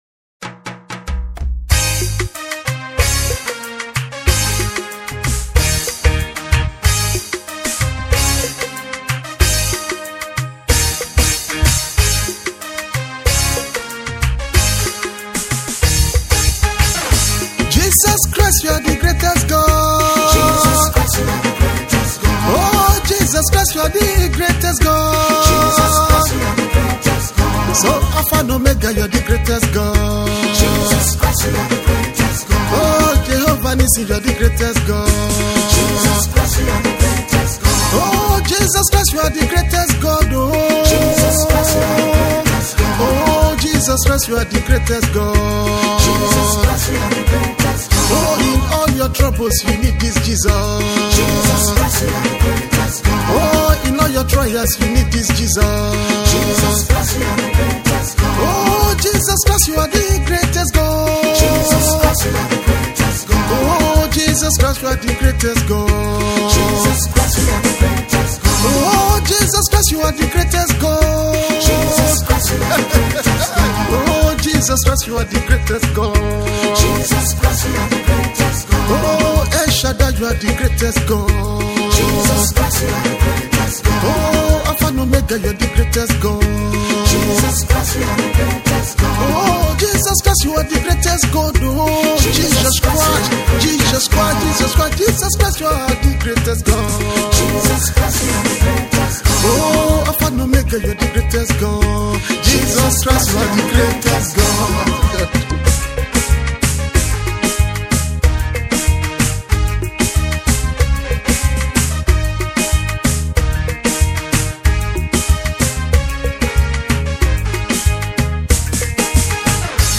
Amazing popular Gospel singer